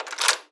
door_lock_open_01.wav